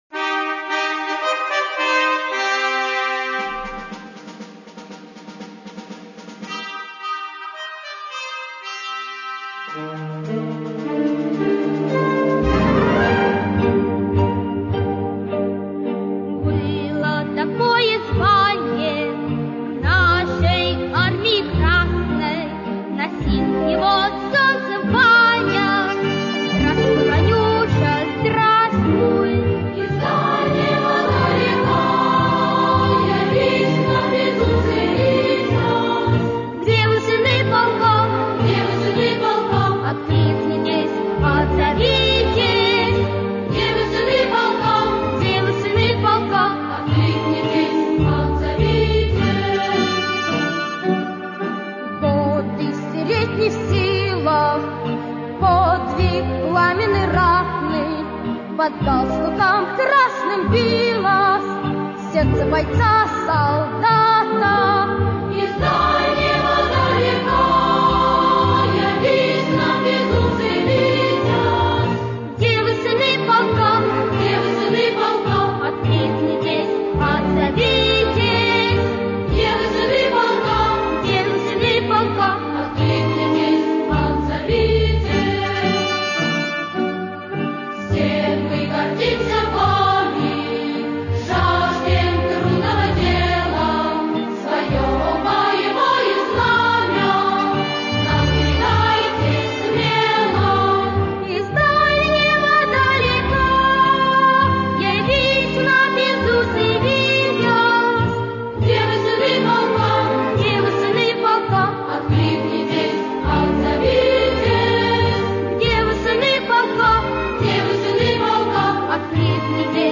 Из кантаты